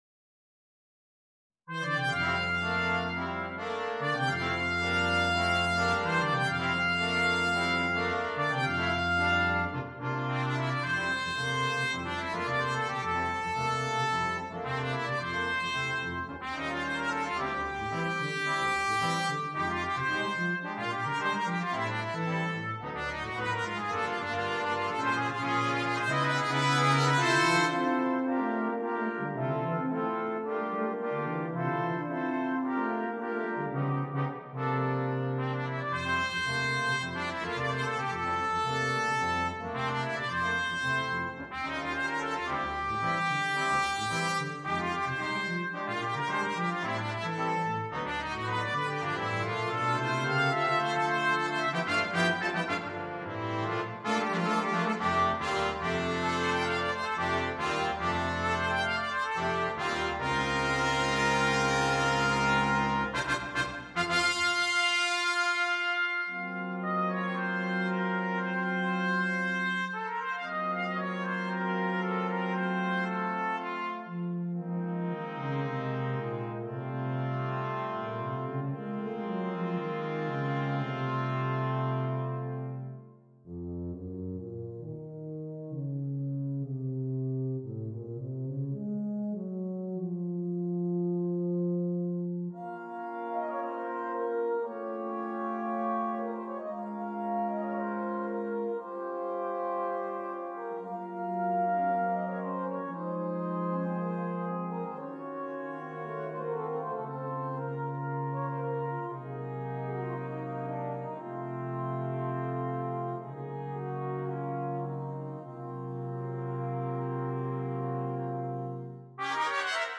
Besetzung: Brass Quintet